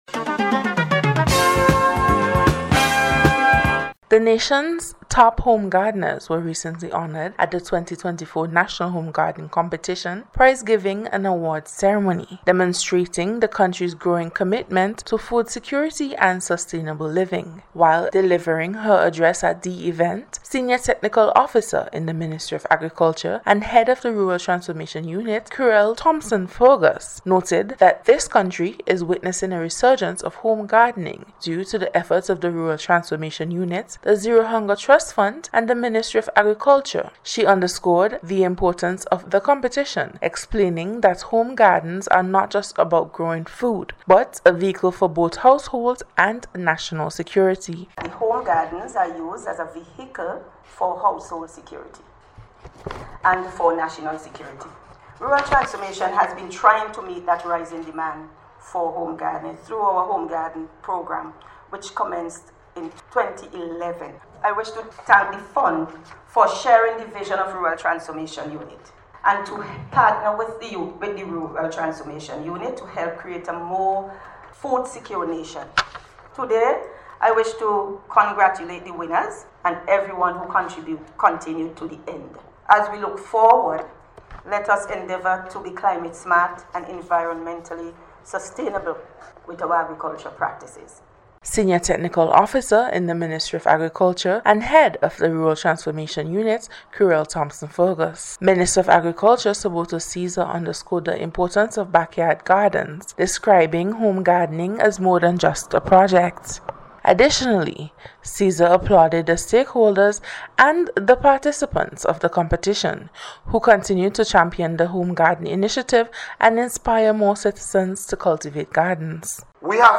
NBC’s Special Report- Tuesday 25th February,2025